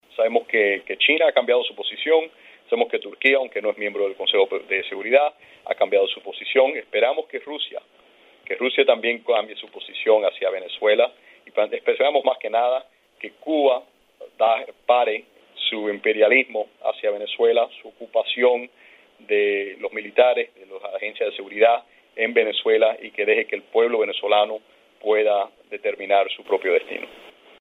Dos minutos antes de la hora fijada para una conferencia de prensa telefónica de Trujillo sobre la convocatoria de 11 países del hemisferio para aplicar el TIAR en Venezuela (en el marco de la OEA), el presidente Donald Trump anunció en un mensaje de Twitter el despido del asesor de Seguridad Nacional John Bolton, uno de los artífices de la política de la Casa Blanca hacia el régimen de Maduro.